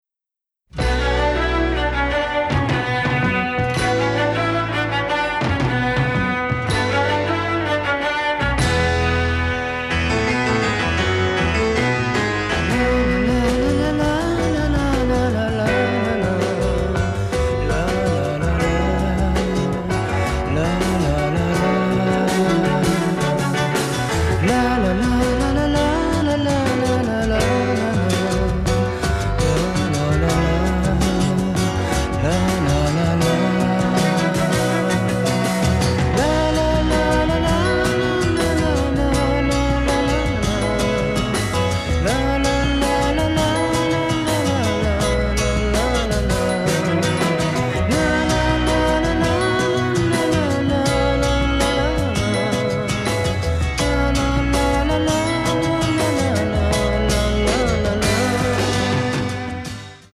and the resulting sound quality is very much improved.